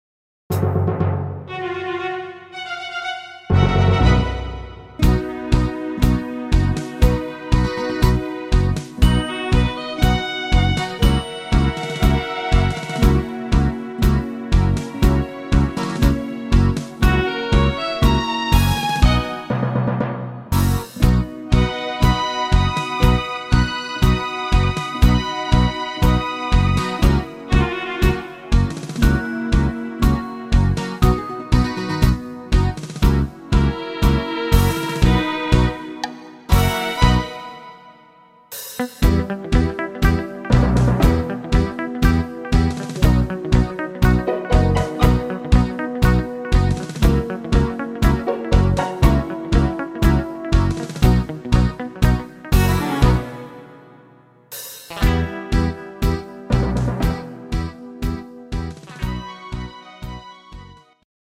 sehr schöner Tango